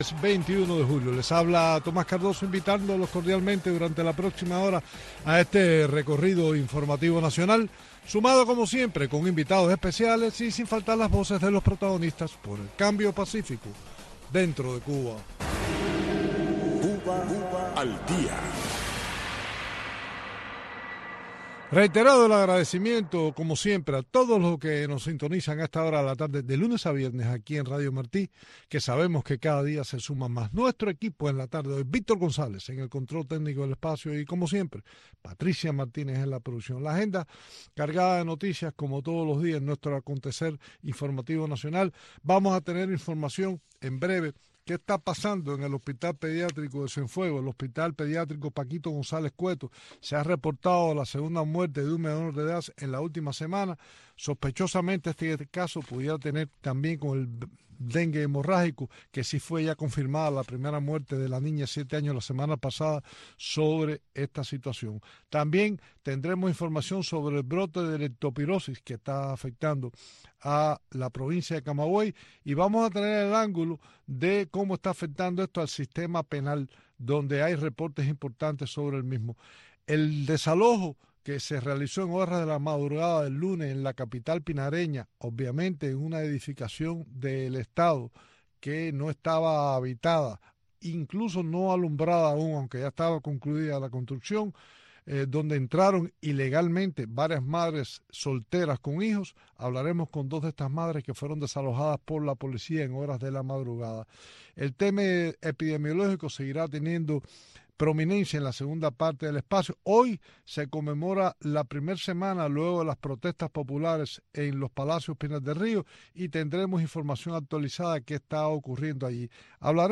en este espacio informativo en vivo, que marca el paso al acontecer cubano.